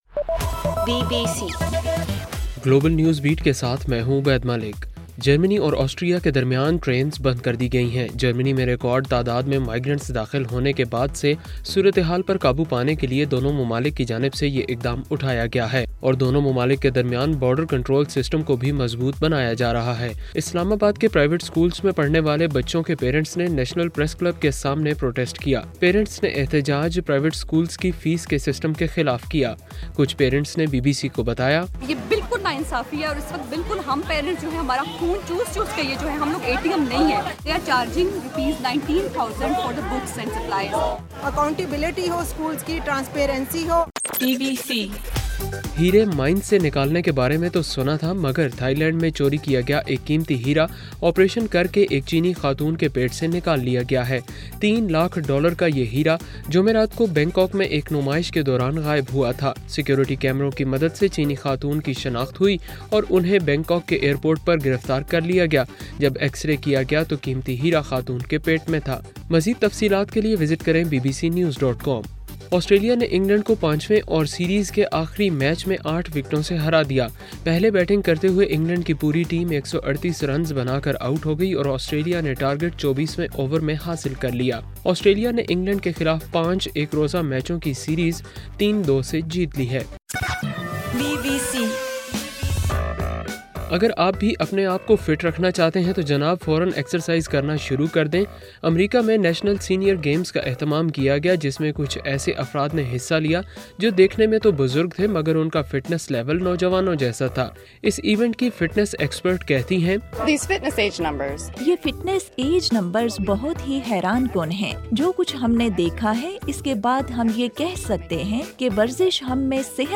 ستمبر 13: رات 12 بجے کا گلوبل نیوز بیٹ بُلیٹن